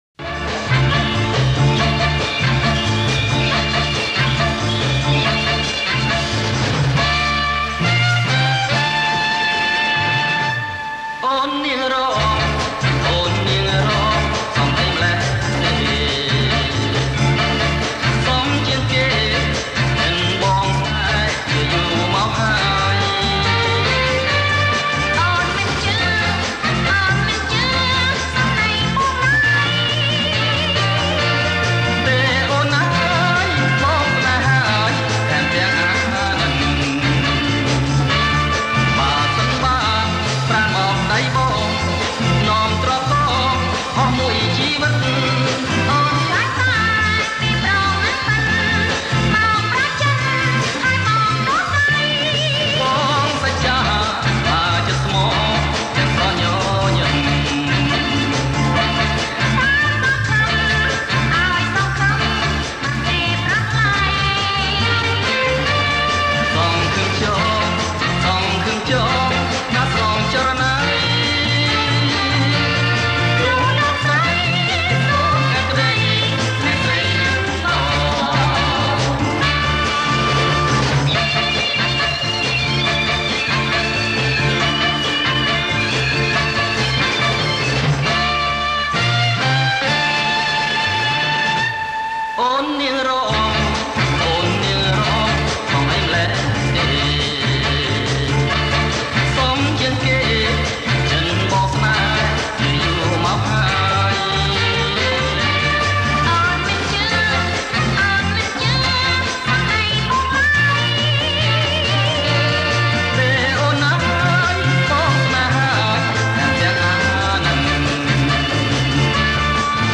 ប្រគំជាចង្វាក់ Twist